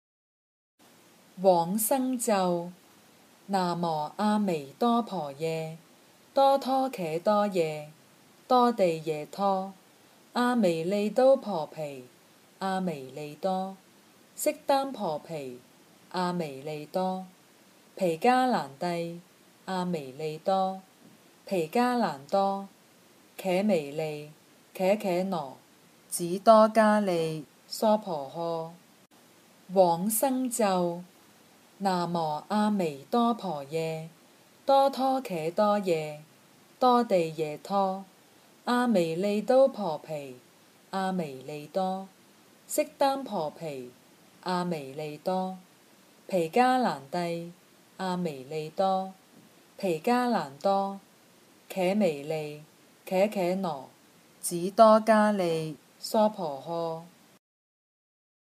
《往生咒》经文教念粤语版